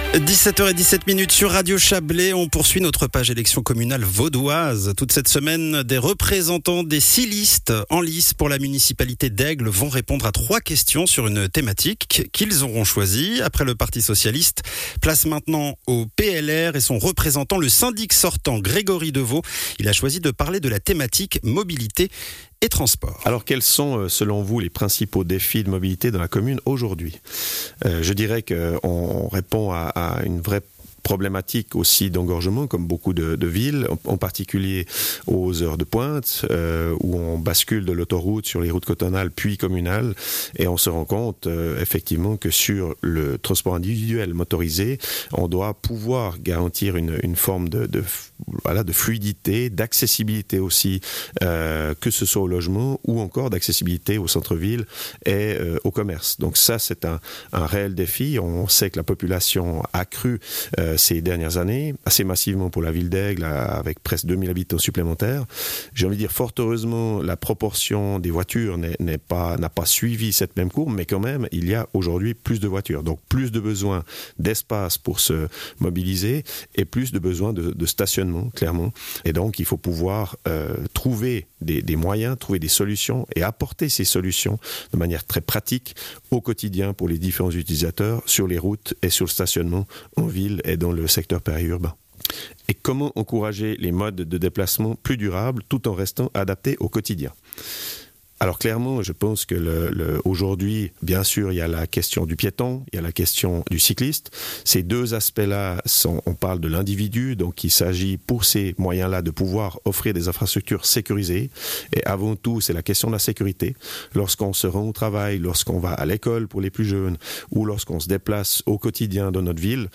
Intervenant(e) : Grégory Devaud, syndic et candidat PLR